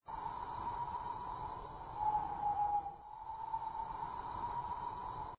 wind1.mp3